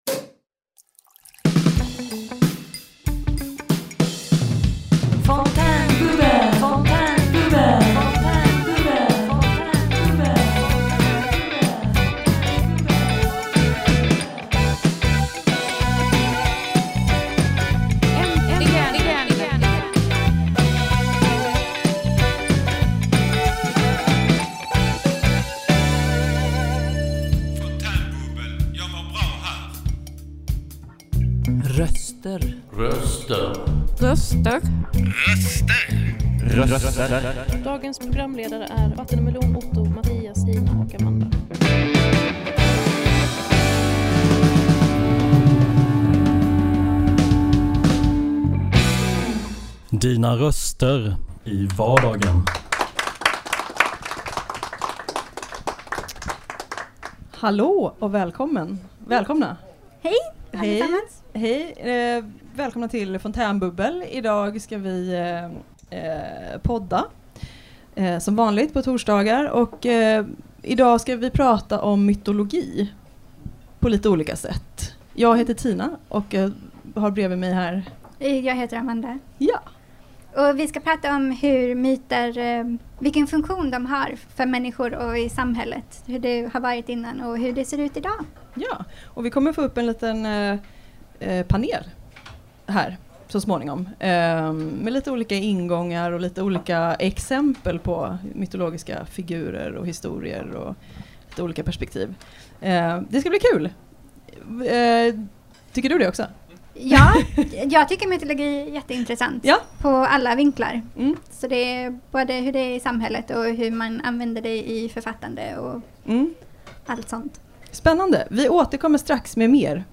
I dagens Fontänbubbel pratar vi om hur myter genomsyrar vårt samhälle och våra liv. Vilken funktion har myter för människor och för dagens samhälle? I ett panelsamtal tittar vi på symbolik och hur vi kan få syn på oss själva genom mytologins spegelvärld.
Personligt, humoristiskt och nära – Fontänbubbel är dina röster i vardagen!